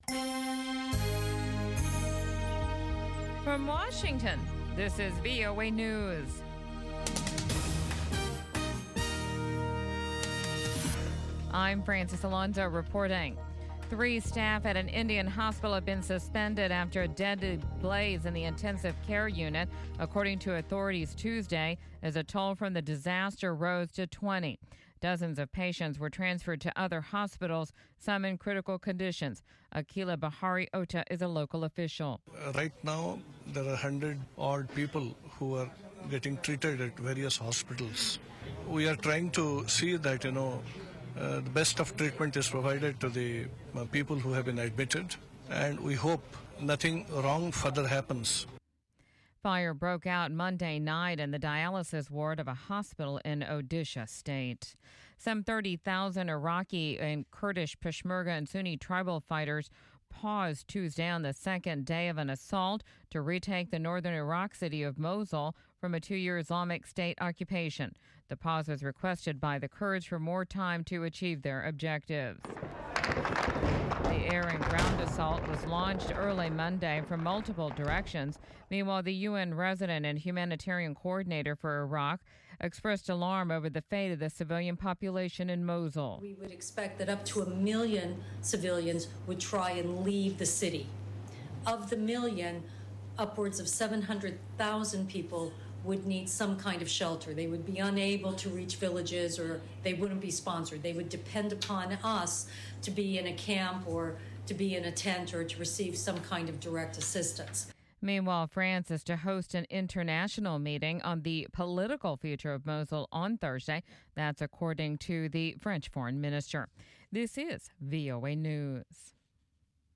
1200 UTC Newscast for October 18, 2016